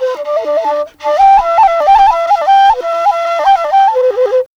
AFRIK FLUTE2.wav